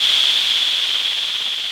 RI_RhythNoise_140-01.wav